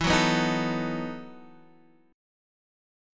EM7sus2sus4 chord